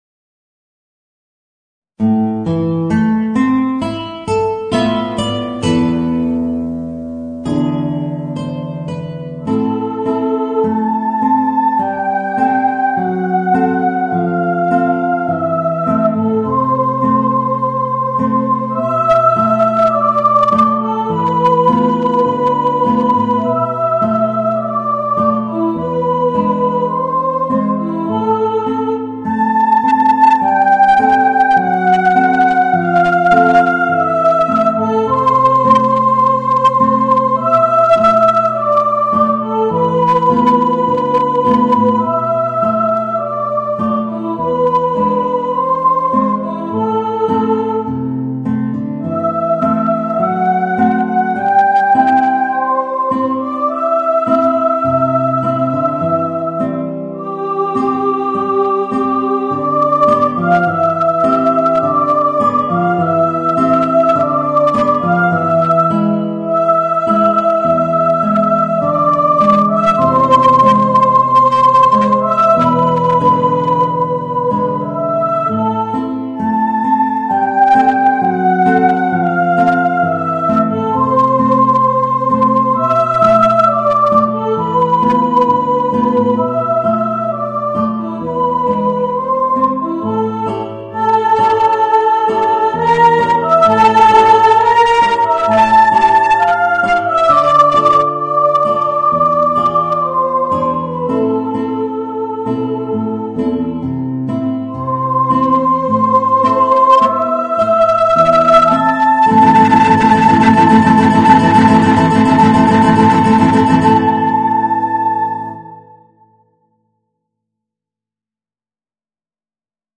Voicing: Guitar and Soprano